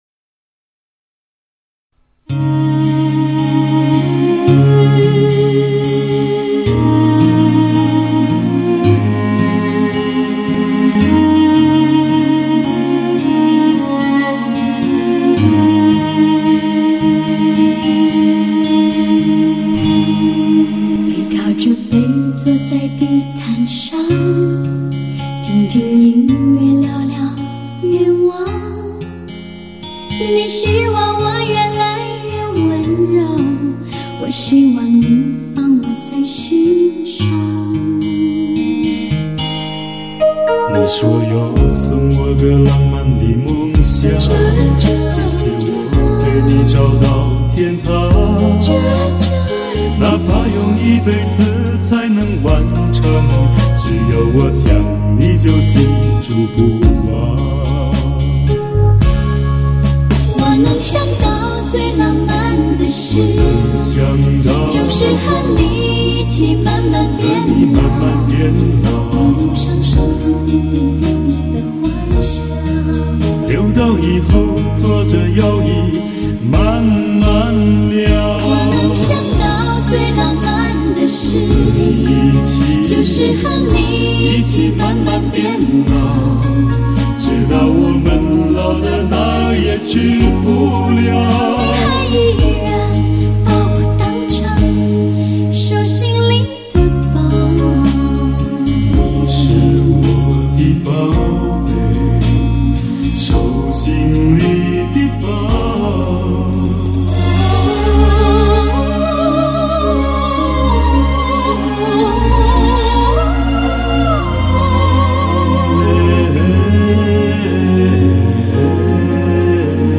资源版本：DTS-ES
采用美国最新核心专利技术独立七声道扩展环绕声系统震撼登场，
歌声浪漫如涓涓溪流，轻轻缓缓，流进听者的耳朵，直沁人心田。